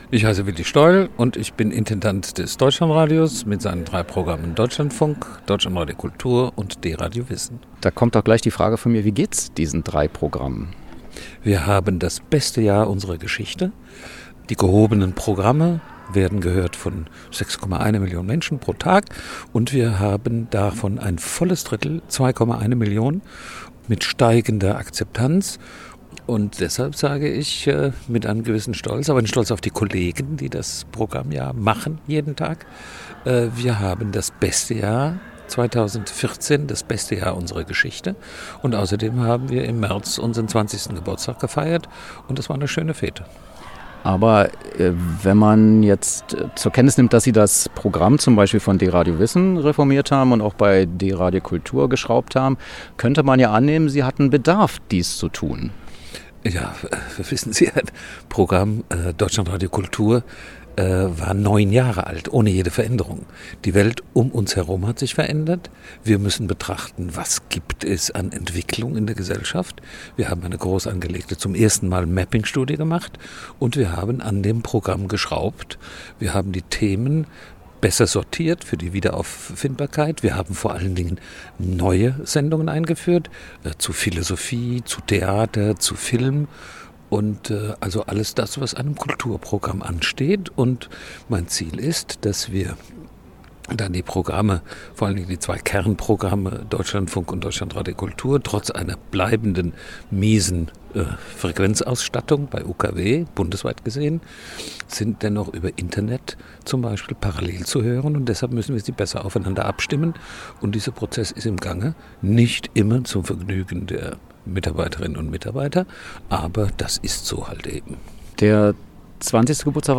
Was: Neujahrsgespräch über das Deutschlandradio und DABplus
Wo: Berlin